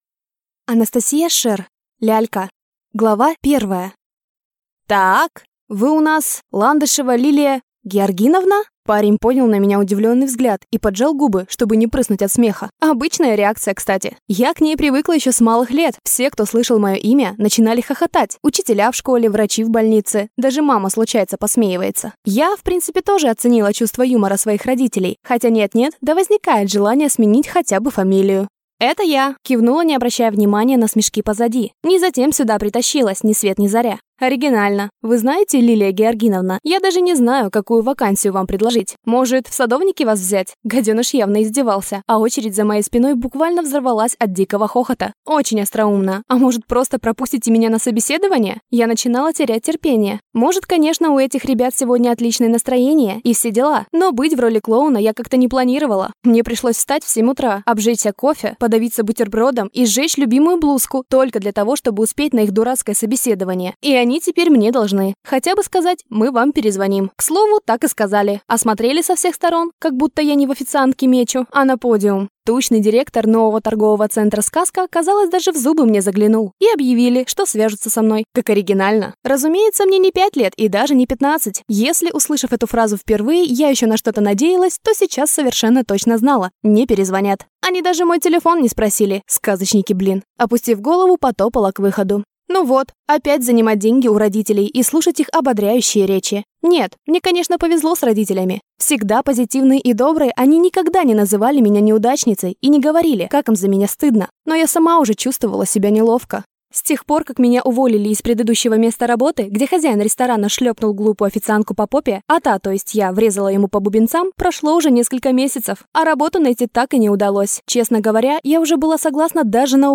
Аудиокнига Лялька | Библиотека аудиокниг